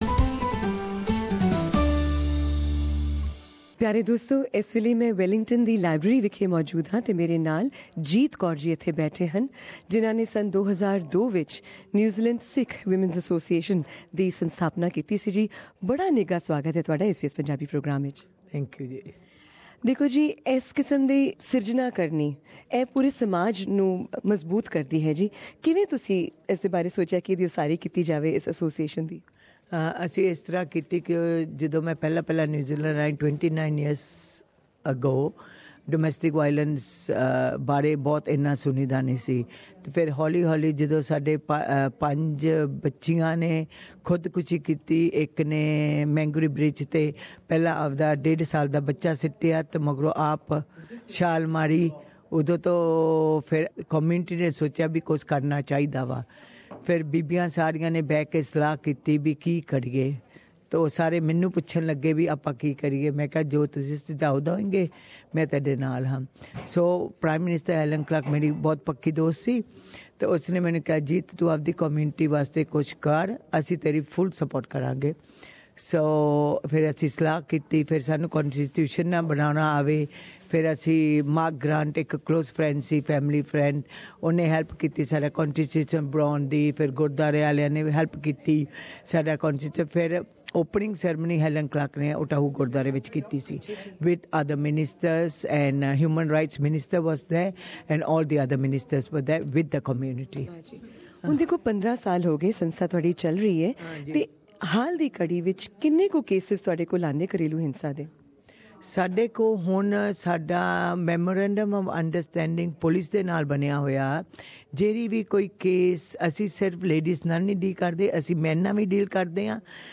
at Wellington, NZ